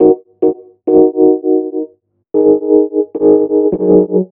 RI KEYS 2 -L.wav